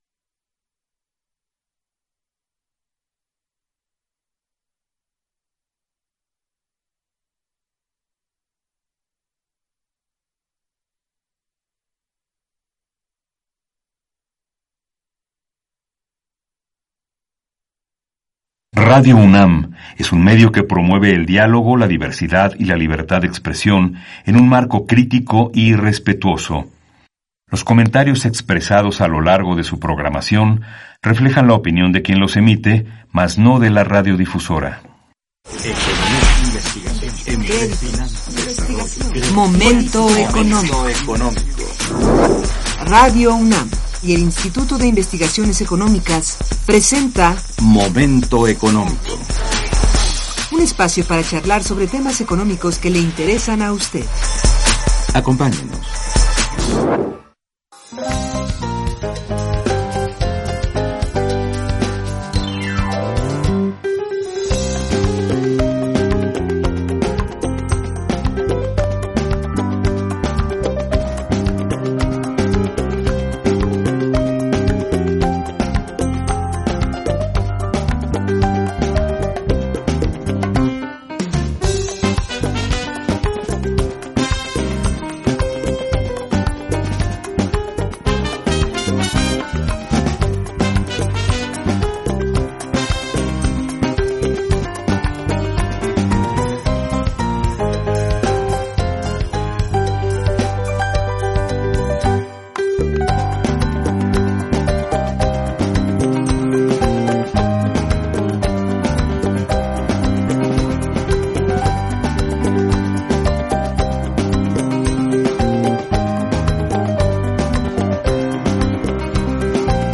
Colecciones: Programa de Radio Momento Económico